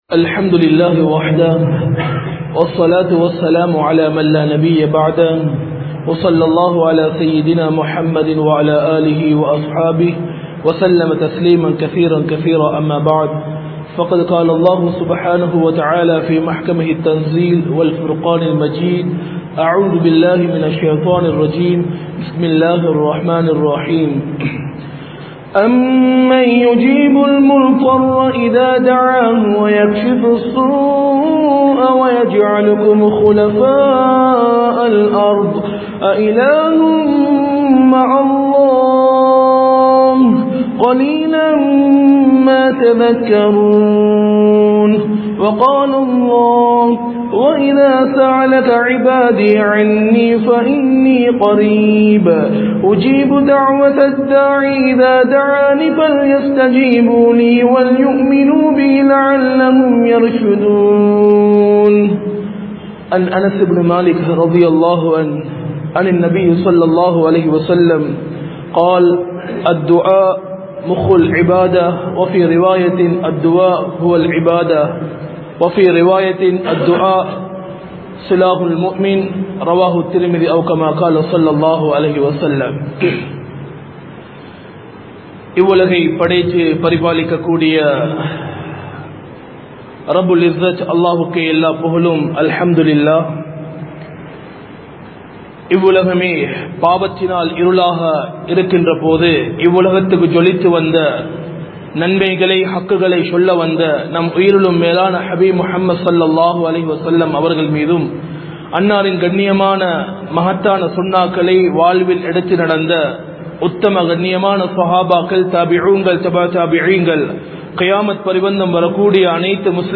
Ungalin Dua Eattrukollapada Veanduma? (உங்களின் துஆ ஏற்றுக் கொள்ளப்பட வேண்டுமா?) | Audio Bayans | All Ceylon Muslim Youth Community | Addalaichenai
Majma Ul Khairah Jumua Masjith (Nimal Road)